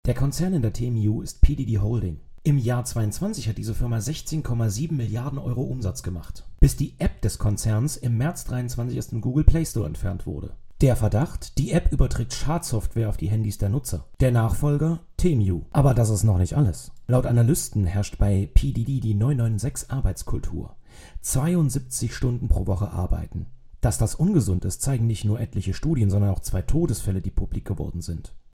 Er ist Experte für Psychologie im Marketing.